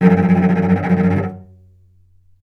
vc_trm-F#2-mf.aif